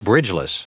Prononciation du mot bridgeless en anglais (fichier audio)
Prononciation du mot : bridgeless